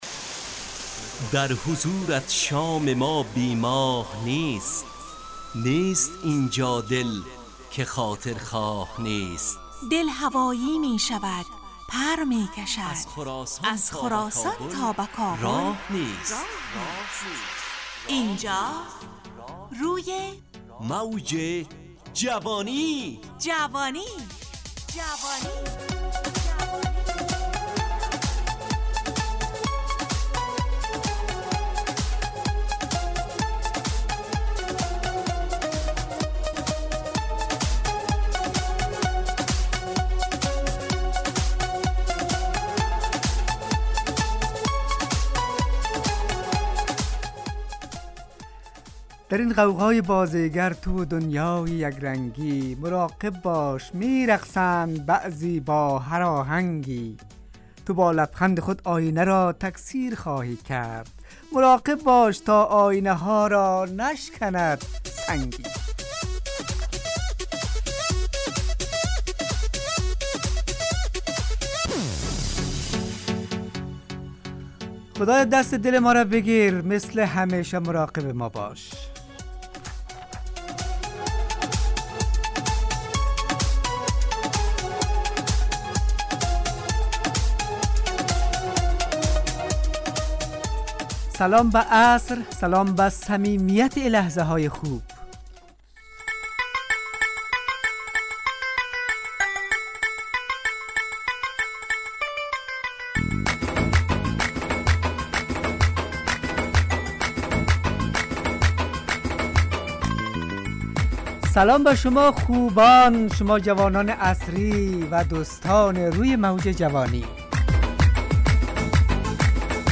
همراه با ترانه و موسیقی مدت برنامه 55 دقیقه .